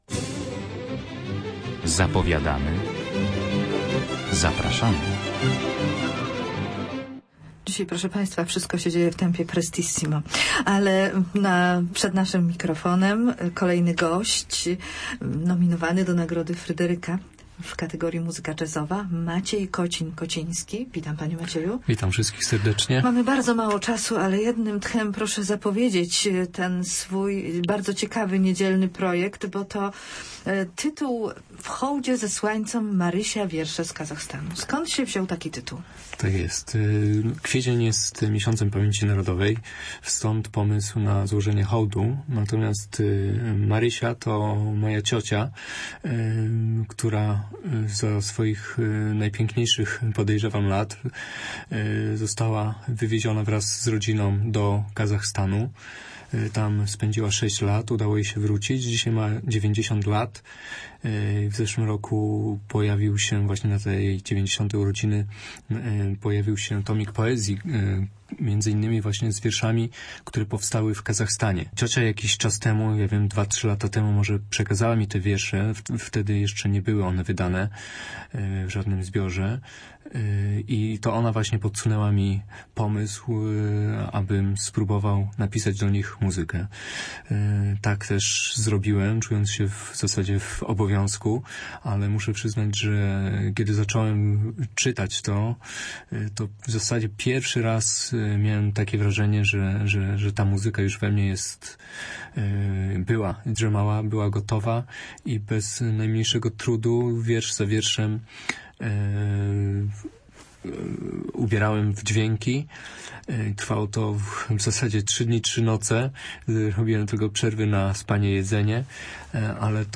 rozmawia z kompozytorem.